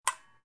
switch-button.wav